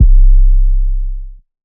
C [808].wav